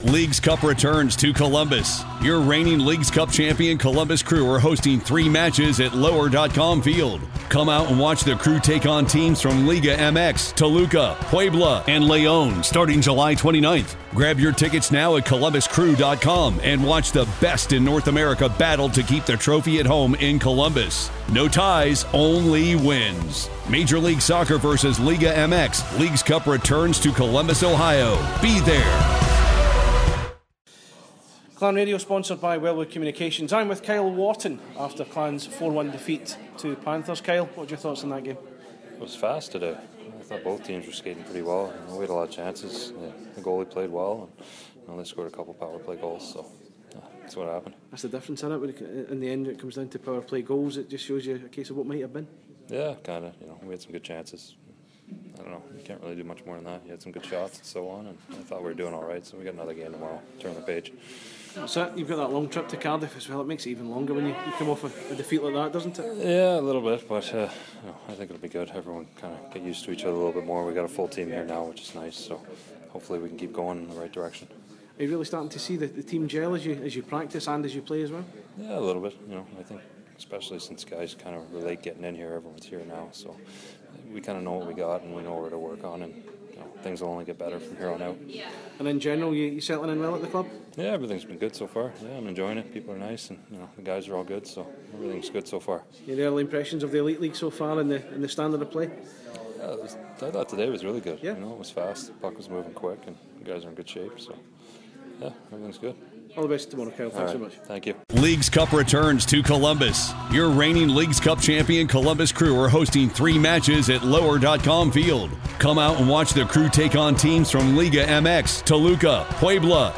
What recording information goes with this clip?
spoke to Clan Radio following tonight's match